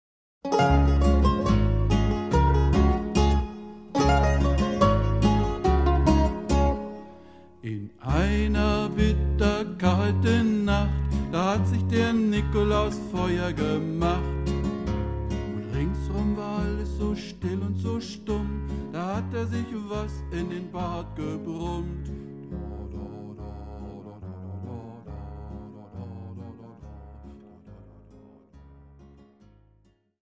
Lieder und Geschichten
Weihnachtsklassiker frisch arrangiert